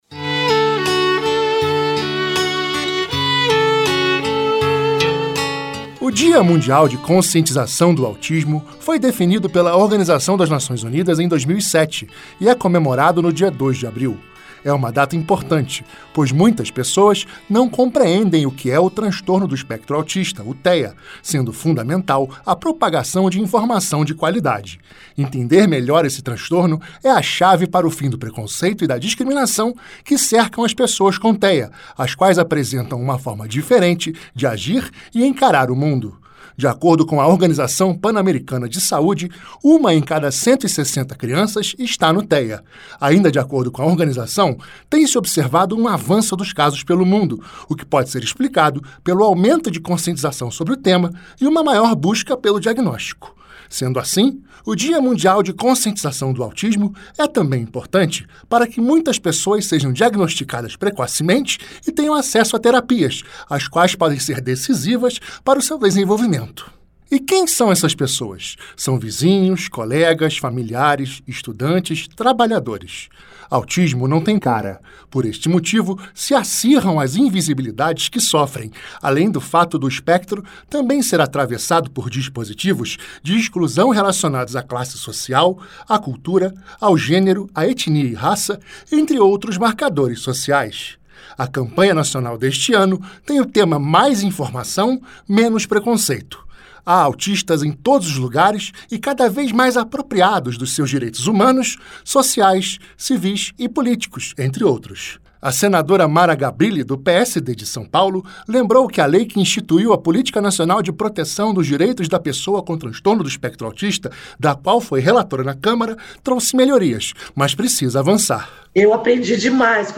Entender melhor esse transtorno é a chave para o fim do preconceito e da discriminação que cercam as pessoas com TEA, as quais apresentam apenas uma forma diferente de agir e encarar o mundo. Esse é o assunto dessa Reportagem Especial da Rádio Senado.